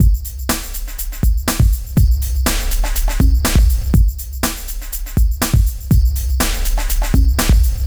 TSNRG2 Breakbeat 009.wav